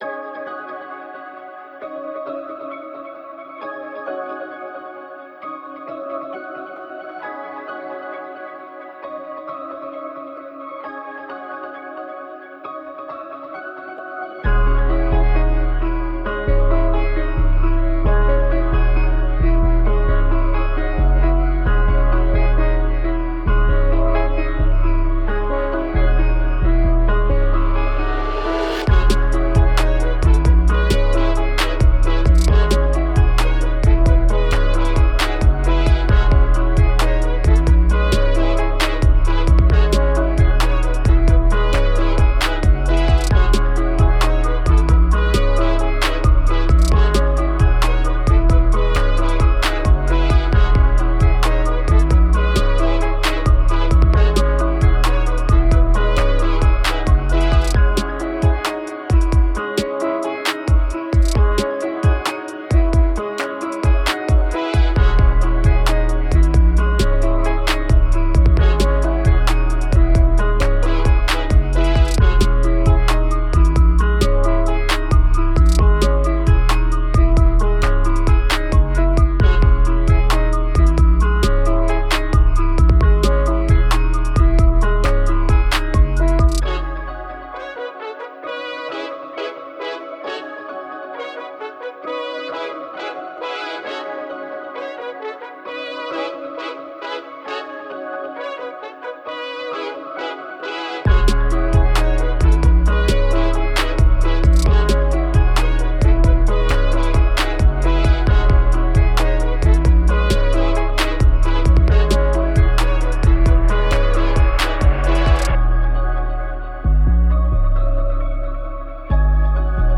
Trap – Bouncy Type Beat
Key: C#m
133 BPM